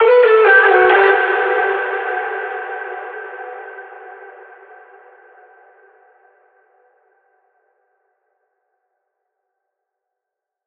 VR_vox_hit_melody2_Emin.wav